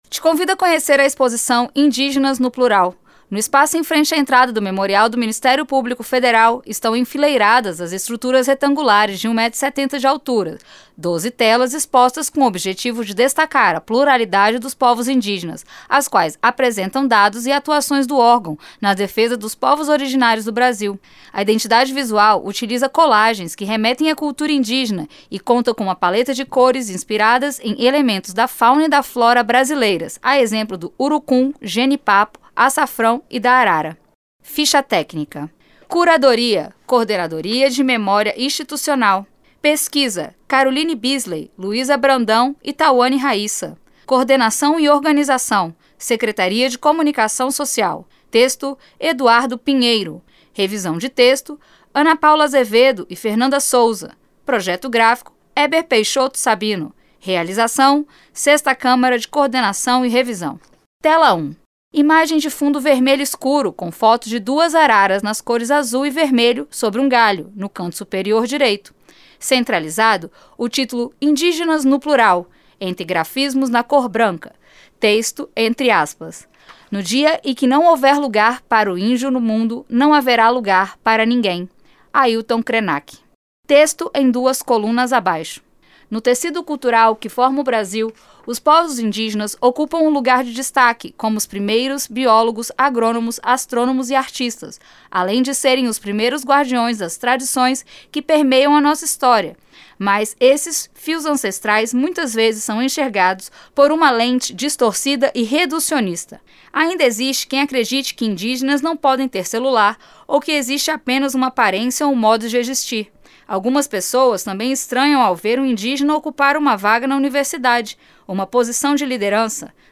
audiodescricao-completa.mp3